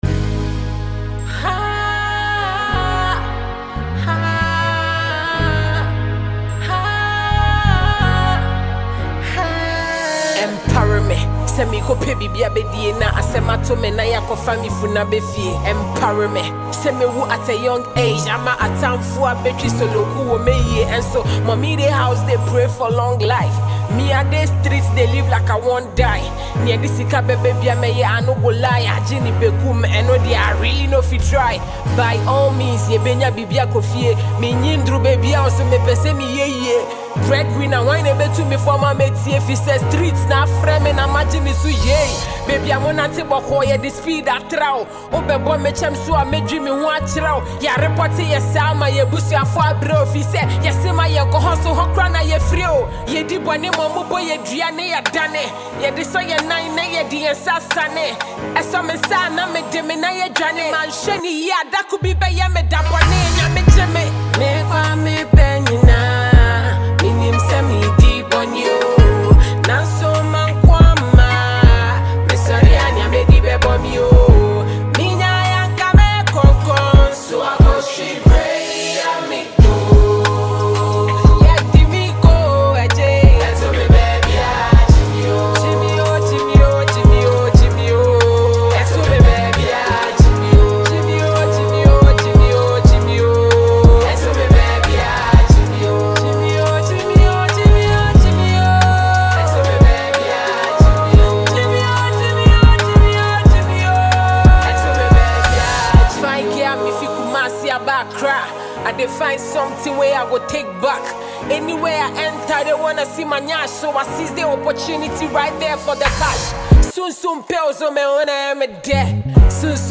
GHANA MUSIC
Ghana’s rap queen
blends raw emotion with top-tier rap artistry